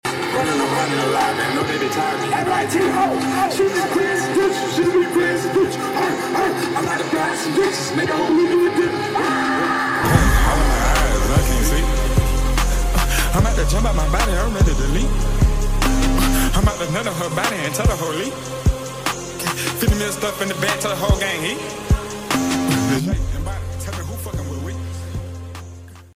Carti screams >> / ib: sound effects free download